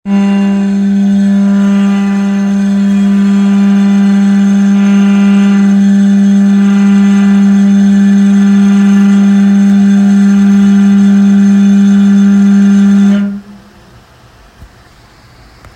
I recorded this in my rented co-working office space. It's super loud and annoying and has been happening for months.
* It's intermittent and can happen a few times a day, or 5 times in 5 minutes.